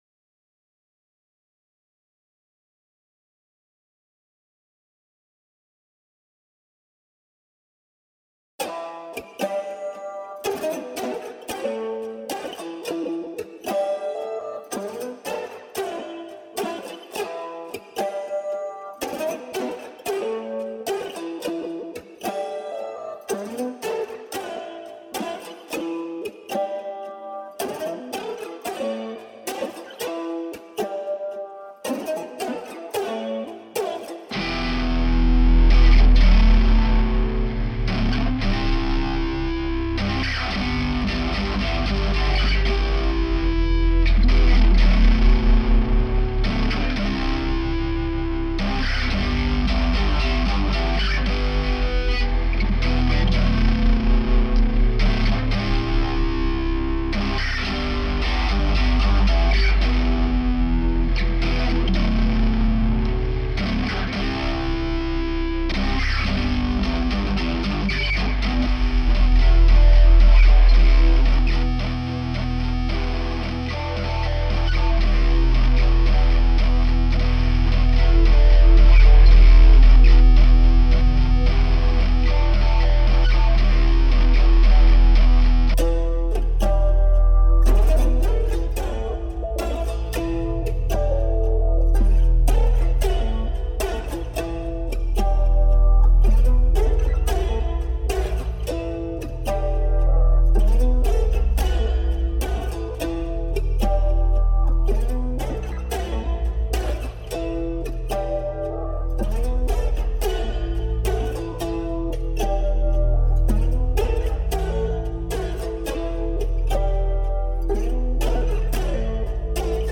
Here’s a very rough track. I’m recording using the laptop’s built-in mic and an acoustic guitar, then processing it with Garage Band effects.
july-effects.mp3